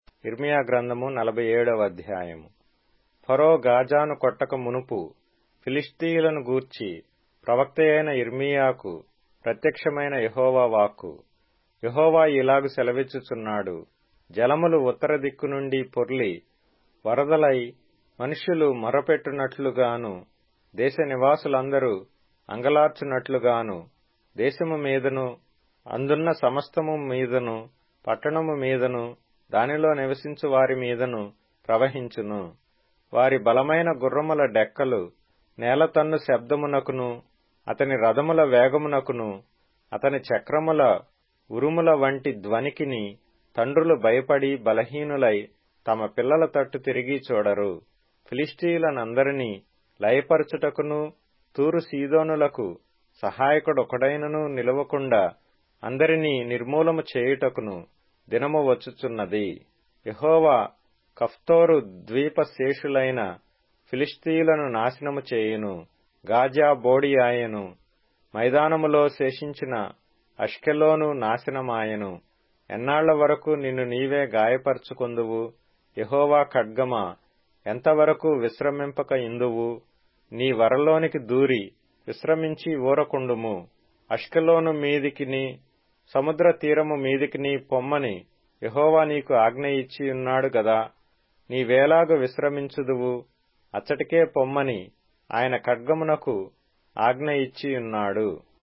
Telugu Audio Bible - Jeremiah 12 in Mrv bible version